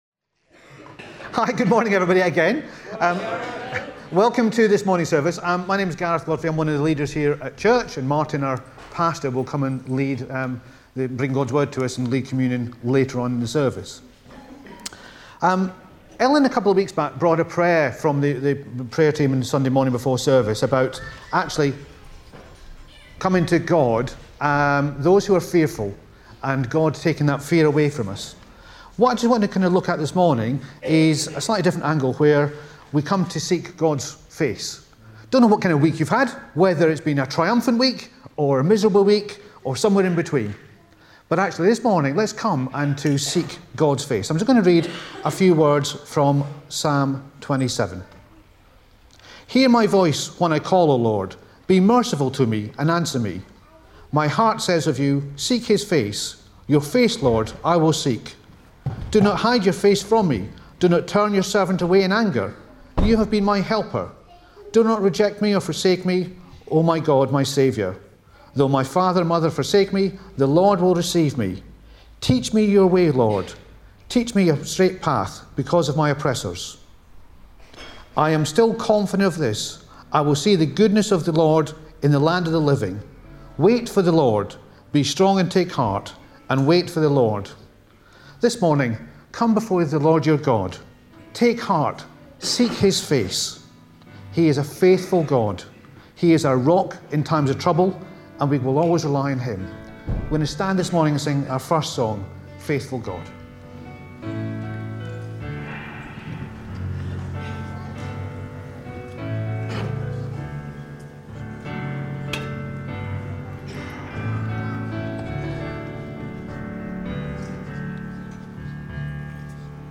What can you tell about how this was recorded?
The service includes communion.